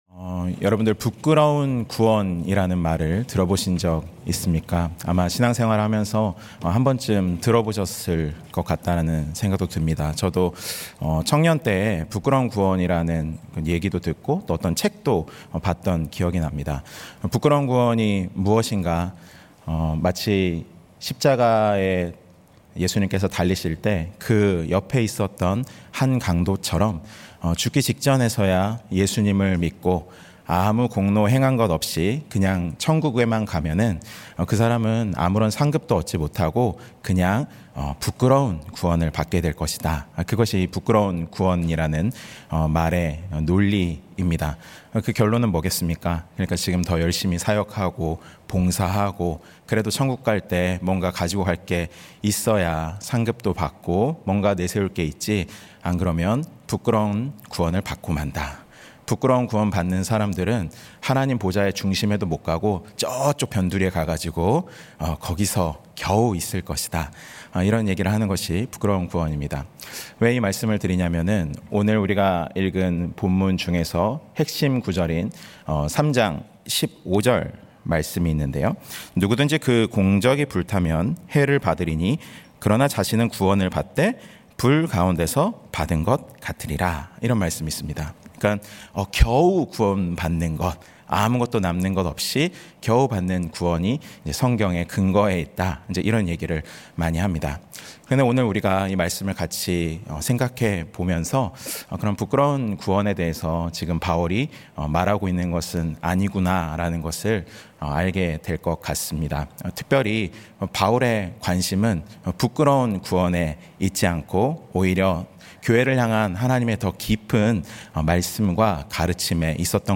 예배 주일예배